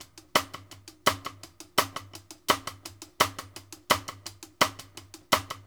Index of /90_sSampleCDs/Sampleheads - New York City Drumworks VOL-1/Partition F/SP REGGAE 84
BEAT JUST -R.wav